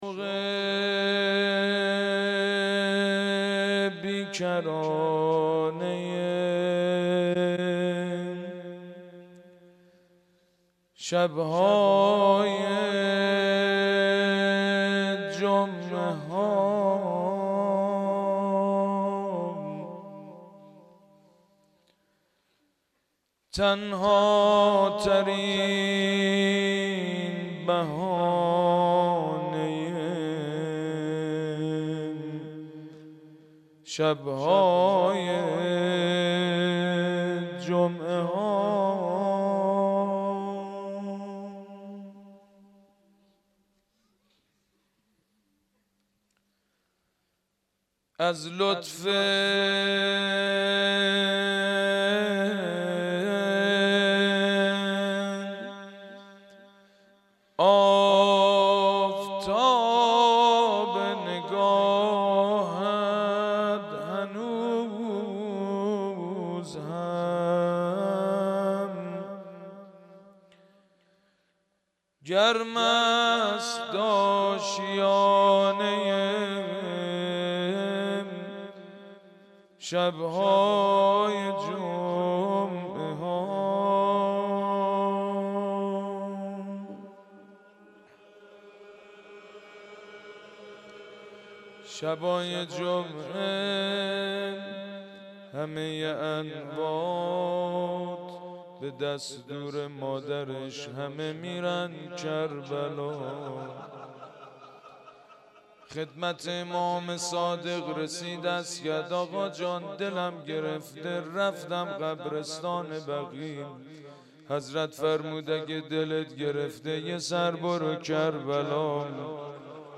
دانلود مداحی ما گدای توییم مادر - دانلود ریمیکس و آهنگ جدید